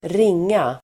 Uttal: [²r'ing:a]